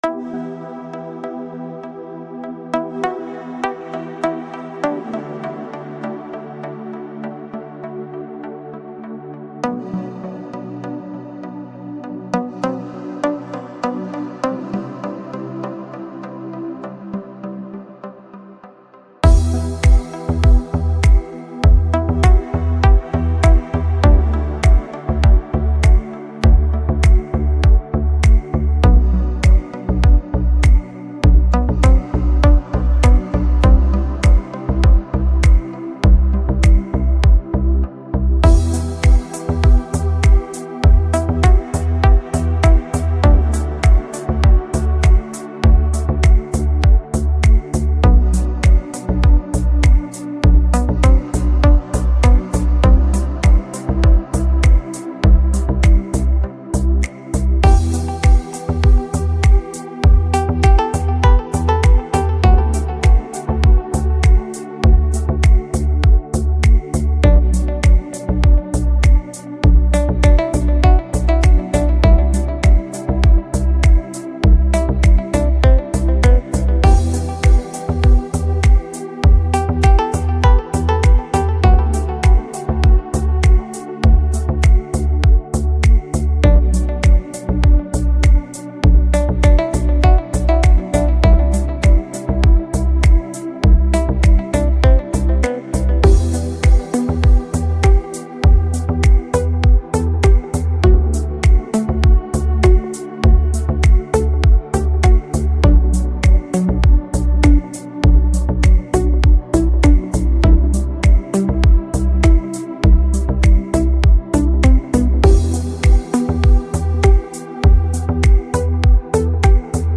Спокойная музыка
спокойные треки
красивая музыка без слов